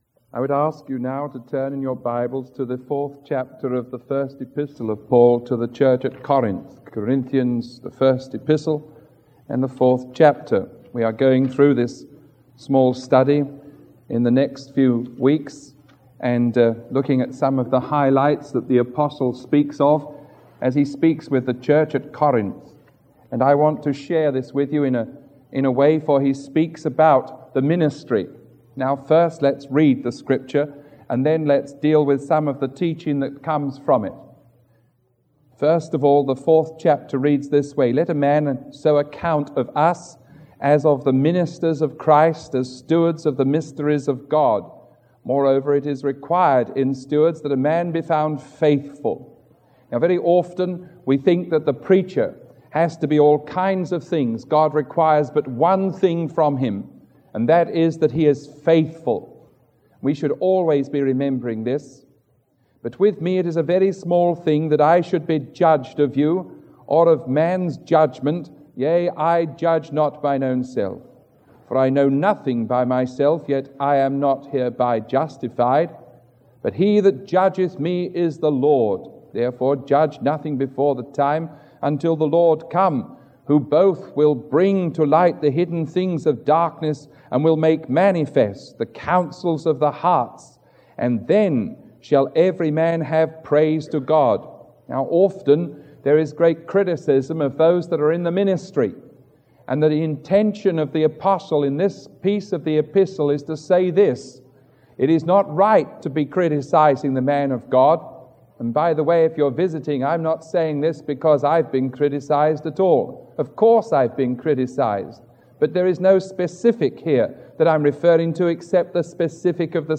Sermon 0430A recorded on October 11